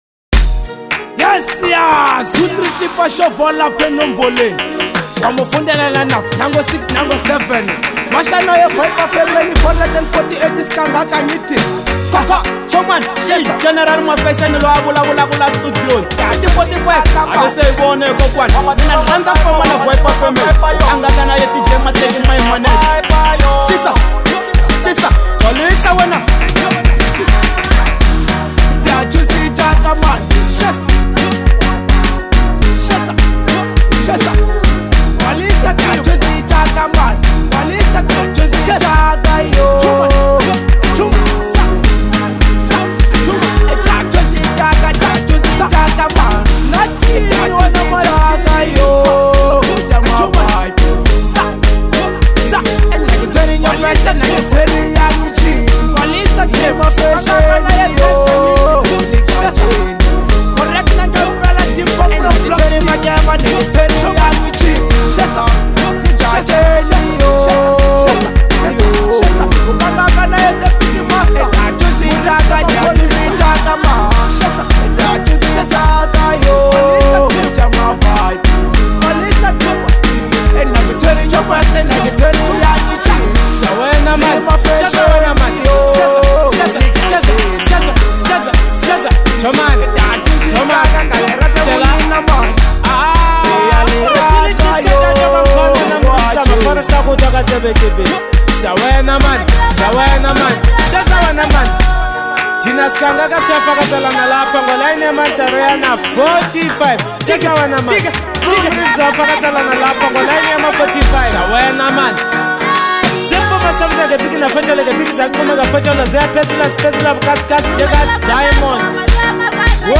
Genre : Bolo House